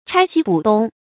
拆西补东 chāi xī bǔ dōng
拆西补东发音